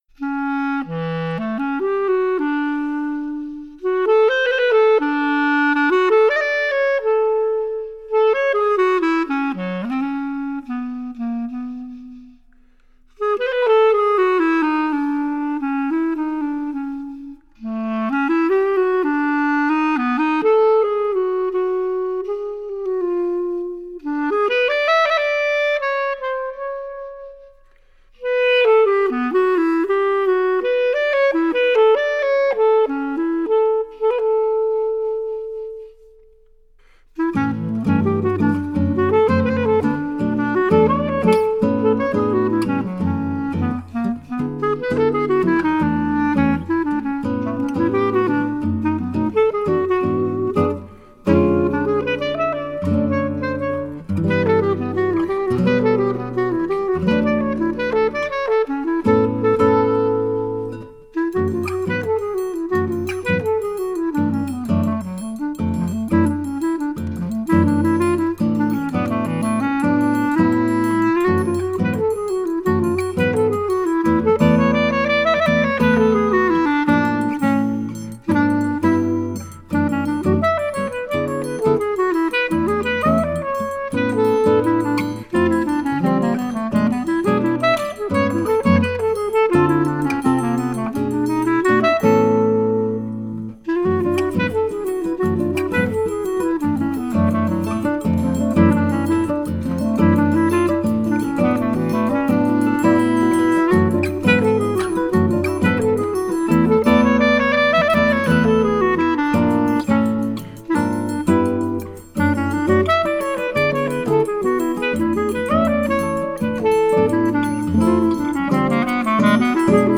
who plays guitar in his arrangement
percussion
violin
viola
cello
clarinet